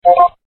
Category: Message Tones